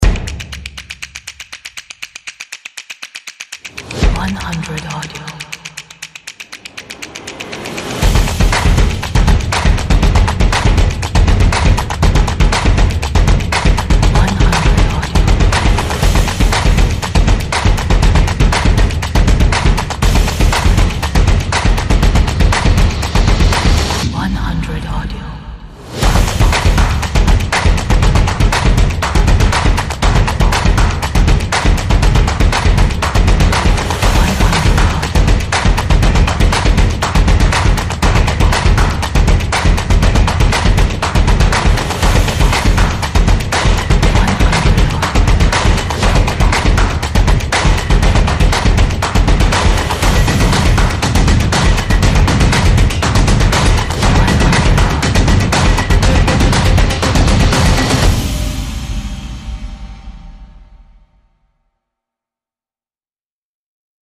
Dynamic, energetic, modern claps and stomps with percussion.